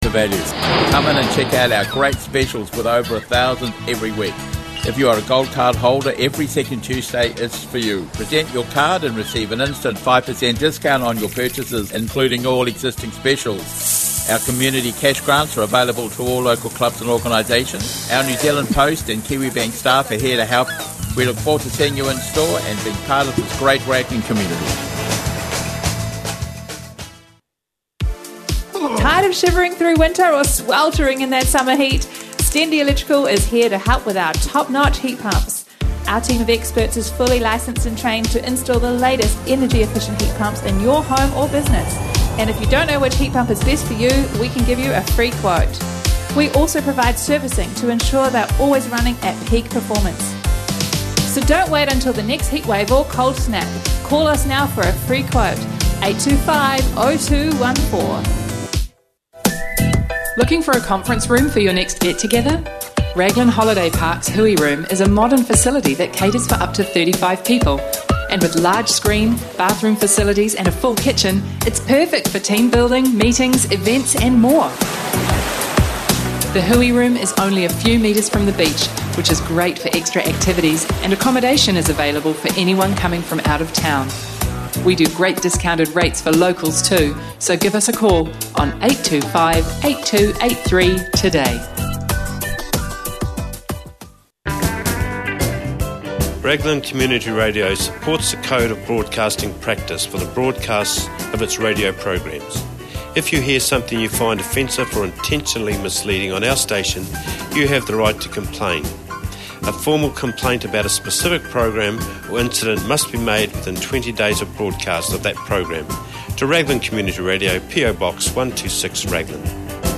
Another week of local news and interviews.